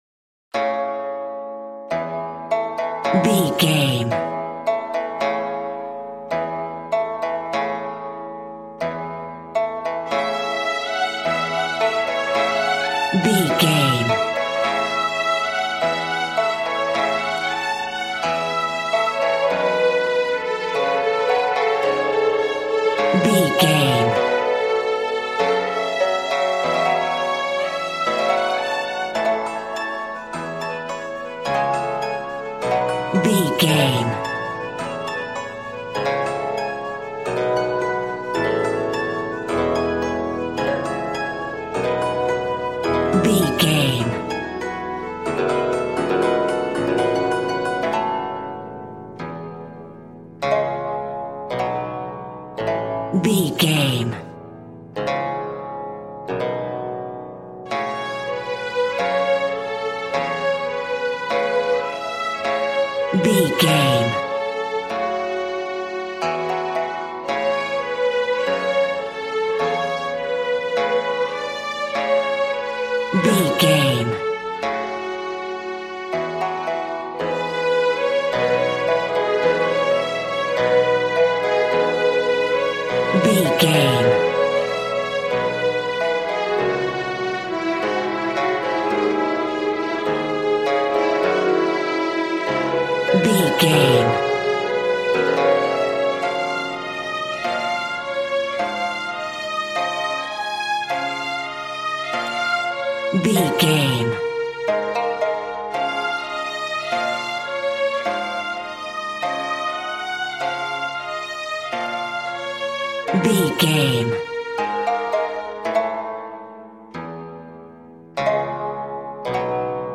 Ionian/Major
happy
bouncy
conga